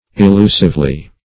illusively - definition of illusively - synonyms, pronunciation, spelling from Free Dictionary Search Result for " illusively" : The Collaborative International Dictionary of English v.0.48: Illusively \Il*lu"sive*ly\, adv. In a illusive manner; falsely.